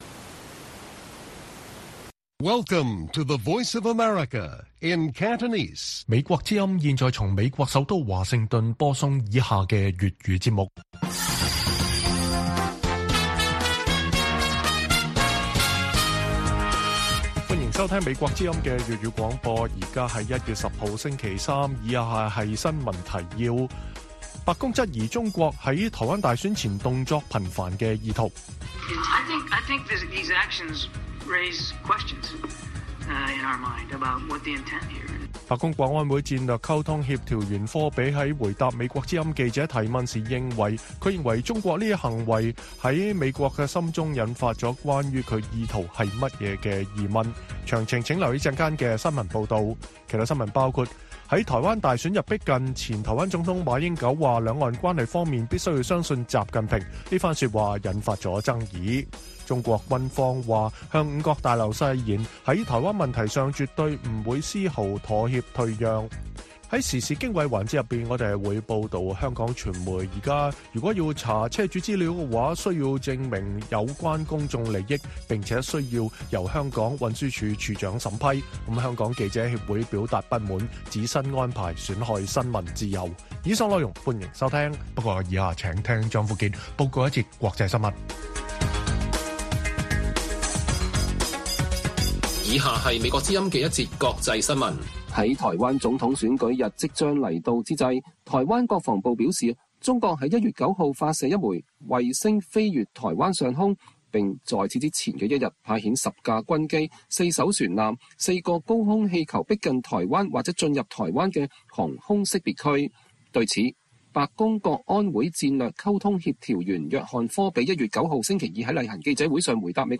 粵語新聞 晚上9-10點: 白宮質疑中國在台灣大選前動作頻頻的意圖